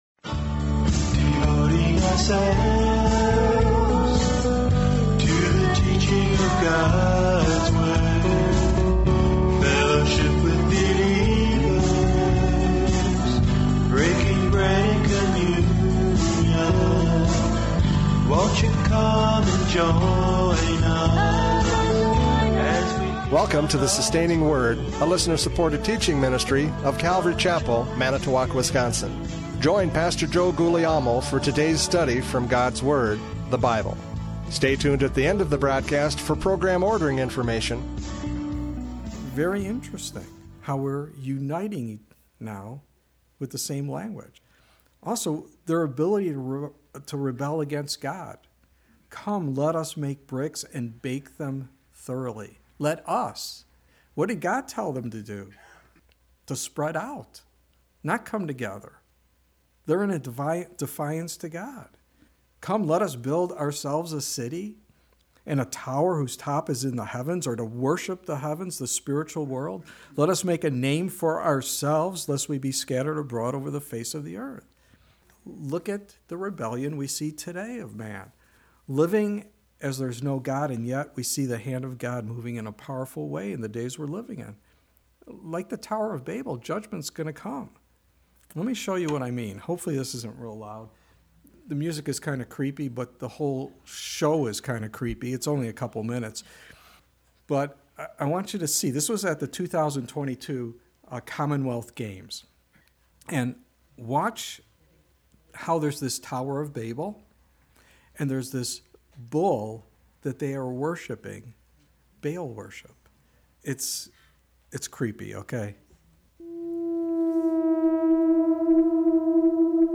Radio Studies Service Type: Radio Programs « Prophecy Update 2024 Convergence!